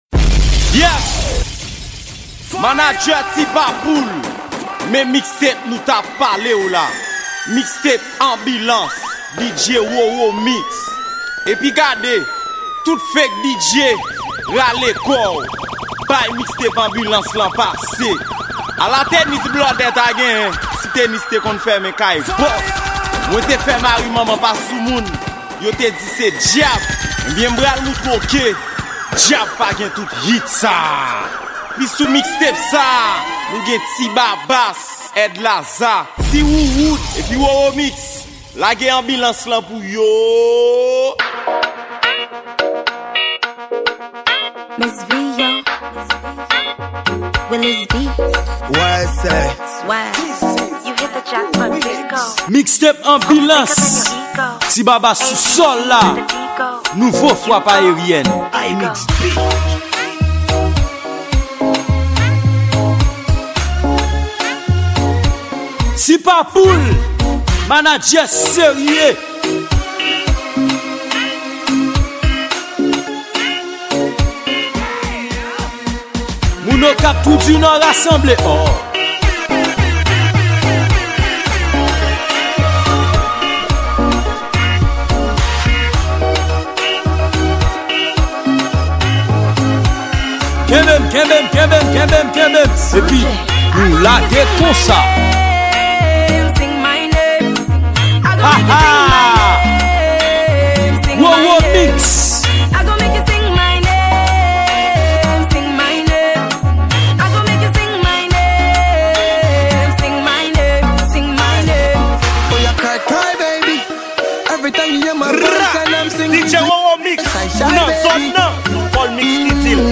Genre: mix.